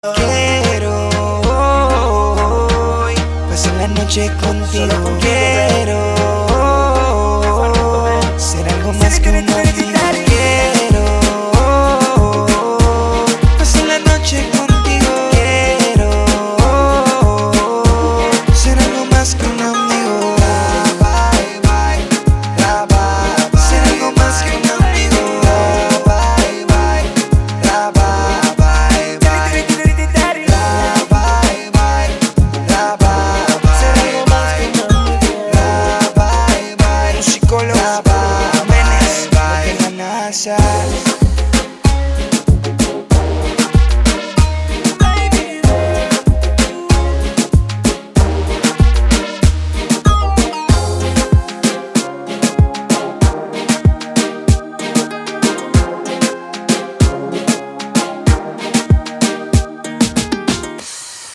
• Качество: 256, Stereo
латинские
латина